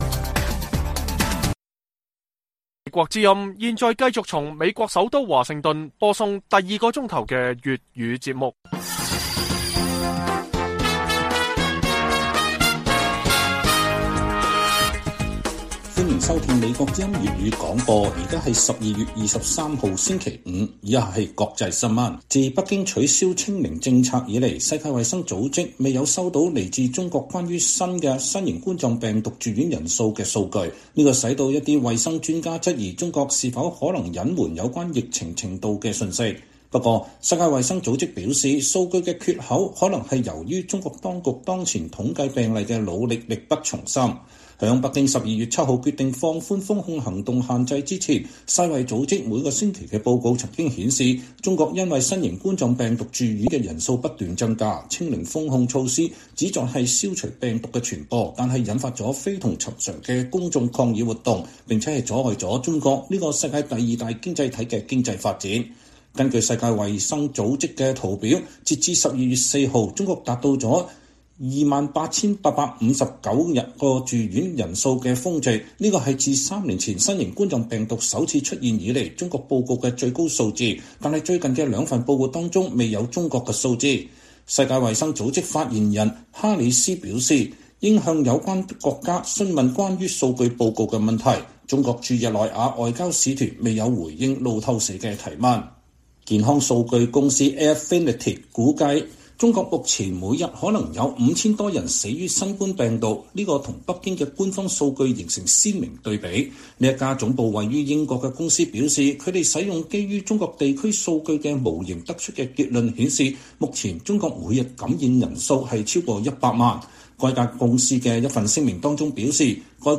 粵語新聞 晚上10-11點 “新十條”下違反防疫者變“無辜”？ 最高法院前法官促糾錯